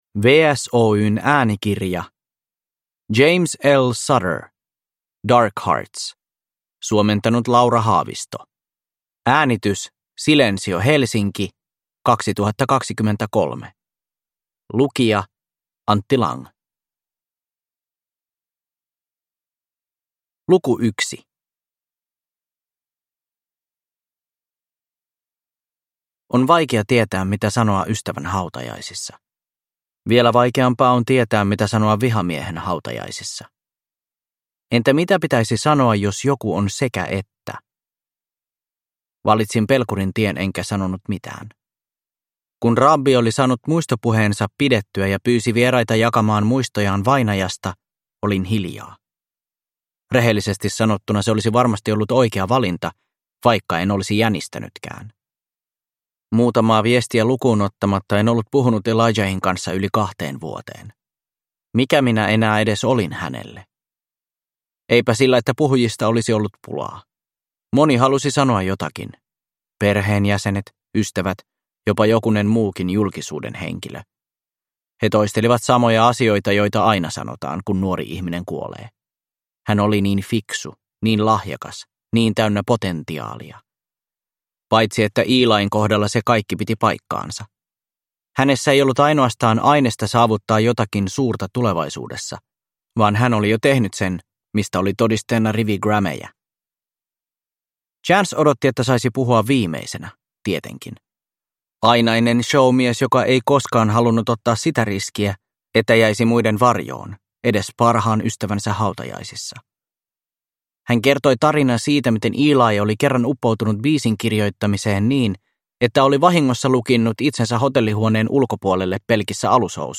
Darkhearts – Ljudbok – Laddas ner